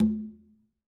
Percussion
Quinto-HitN_v3_rr2_Sum.wav